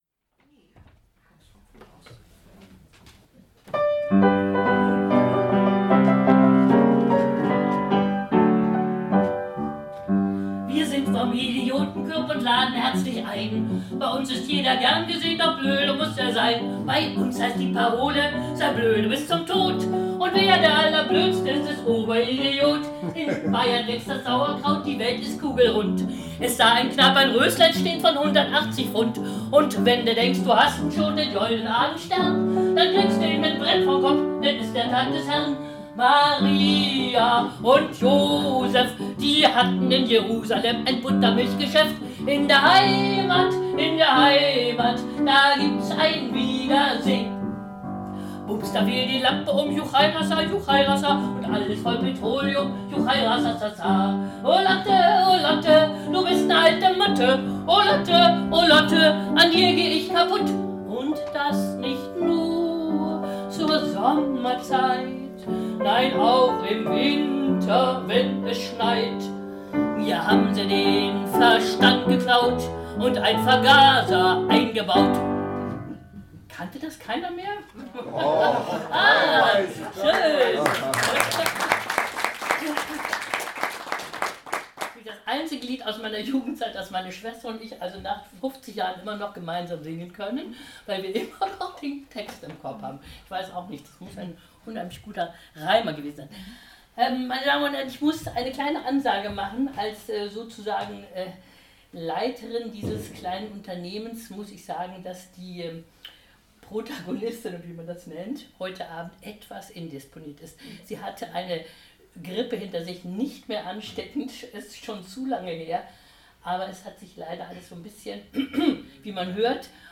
Textbuch (PDF) Mitschnitt einer öffenlichen Veranstaltung (MP3, Audio) Ihr Browser unterstützt keine MP3-Dateien zurück zu den Programmen